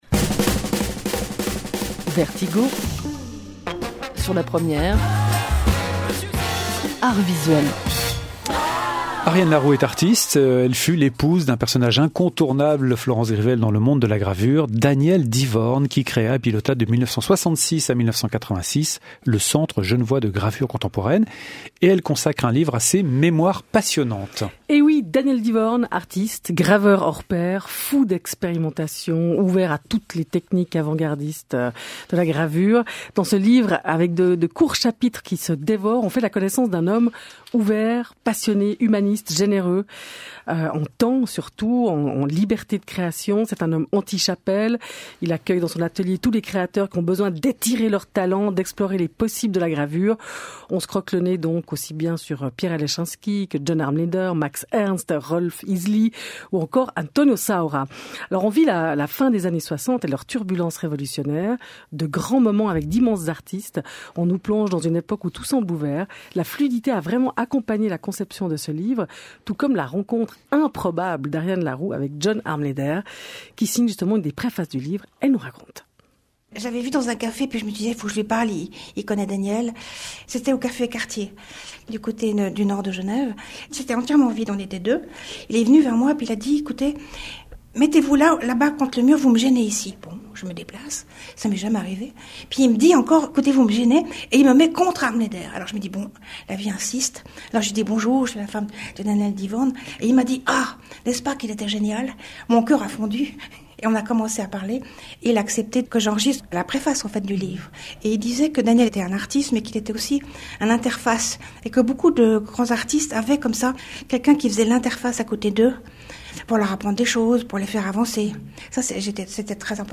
Interview Emission TSR Vertigo 10 avril 2018 :